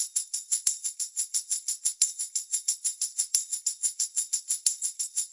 手鼓2小节循环 90BPM 44
描述：由D'Angelo启发的带有拖动凹槽的2条手鼓环。
Tag: 沟槽 铃鼓 敲击